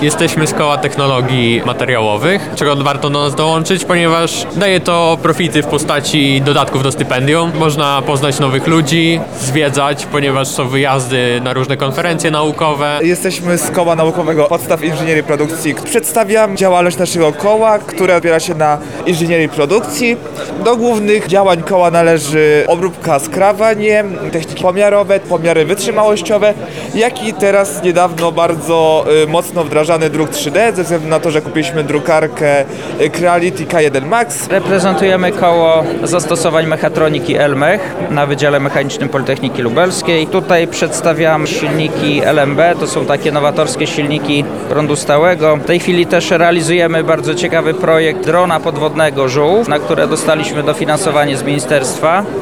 Dzisiaj (13.11) na wydziale Budownictwa i Architektury Politechniki Lubelskiej odbyły się Targi Kół Naukowych i Organizacji Studenckich. Co nam oferują oraz dlaczego warto do nich dołączyć? O to zapytali przedstawicieli poszczególnych kół nasi reporterzy.
sonda koła